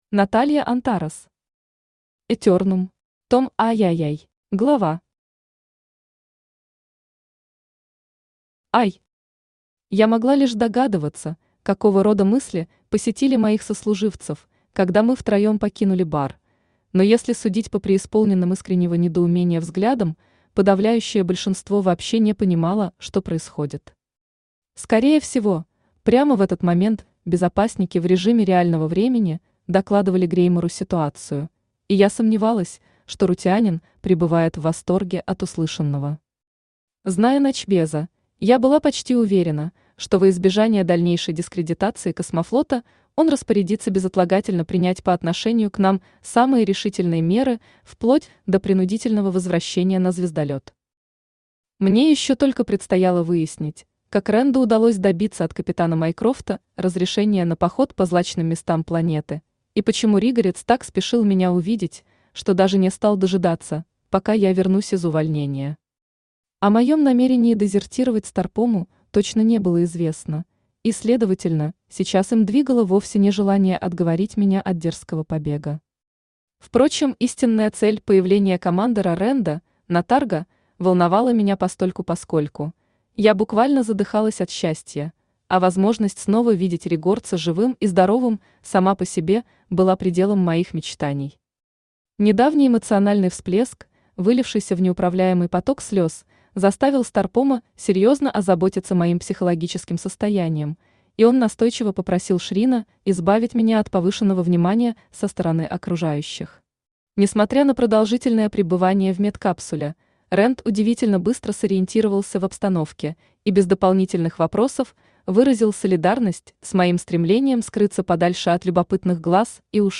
Аудиокнига Этернум. Том III | Библиотека аудиокниг
Том III Автор Наталья Антарес Читает аудиокнигу Авточтец ЛитРес.